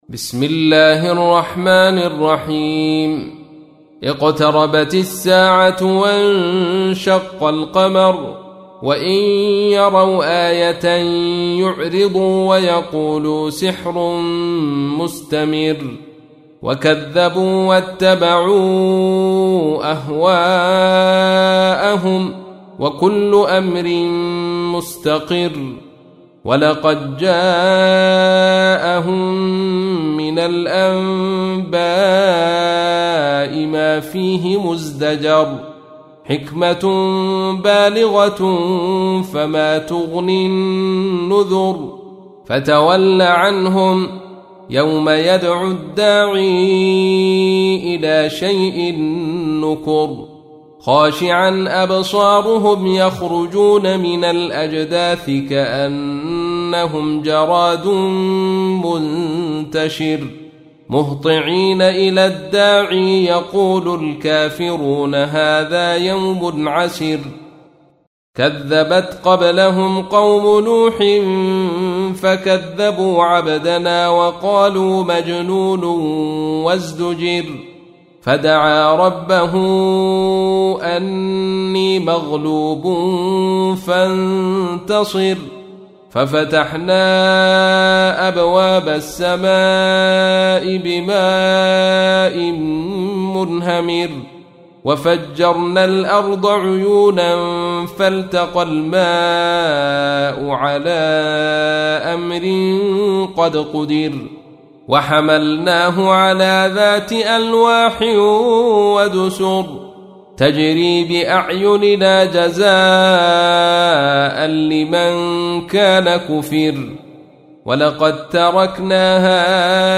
تحميل : 54. سورة القمر / القارئ عبد الرشيد صوفي / القرآن الكريم / موقع يا حسين